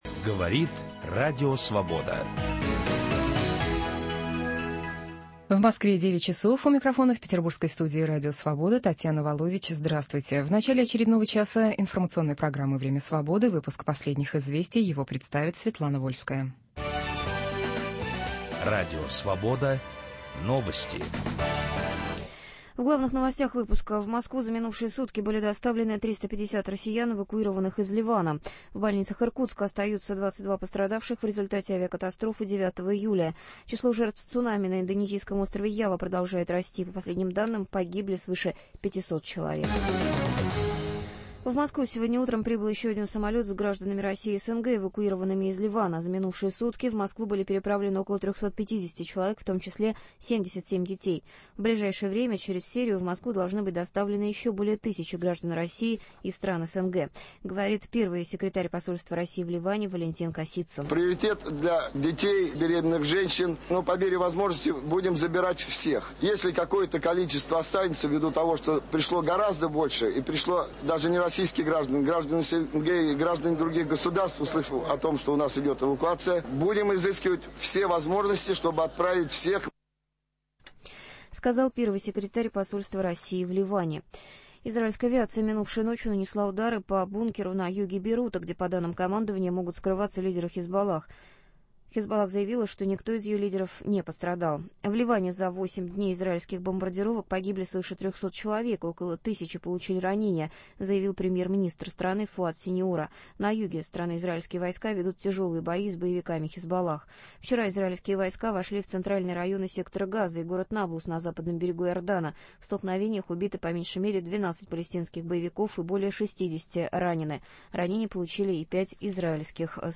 Гость в студии